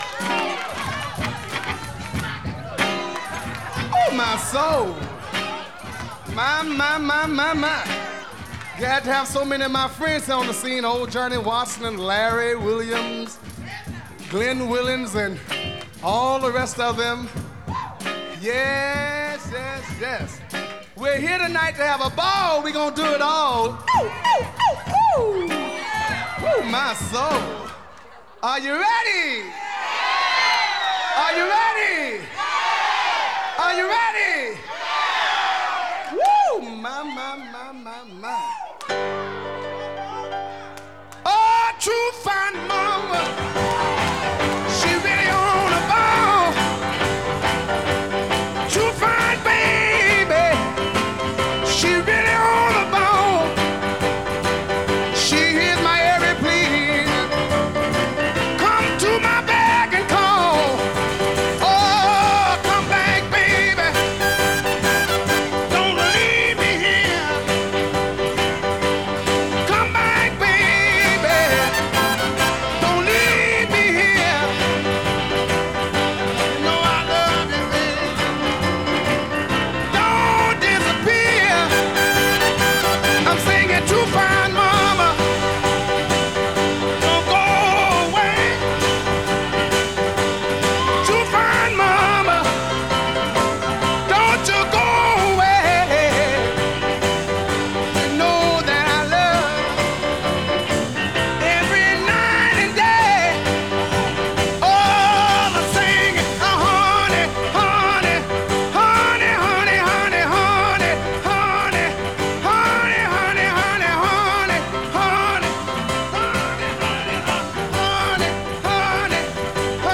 Жанр Рок-н-ролл, госпел